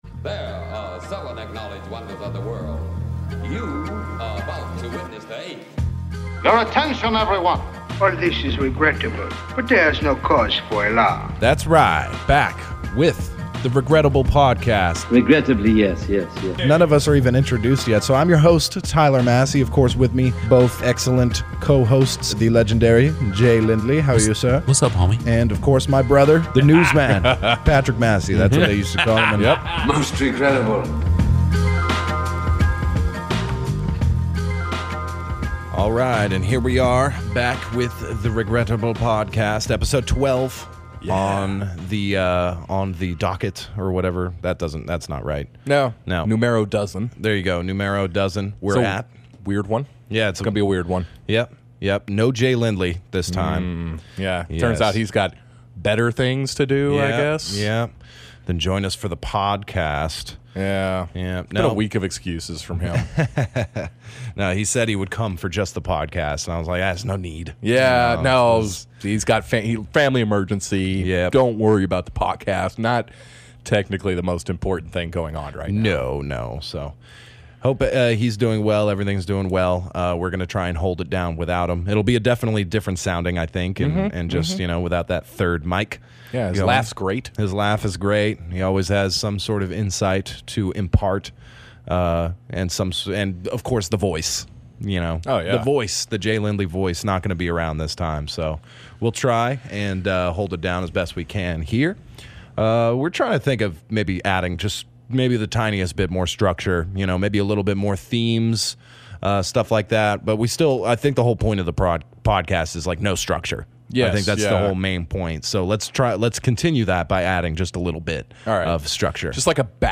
Disclaimer: Sensitive Subjects and Strong Language.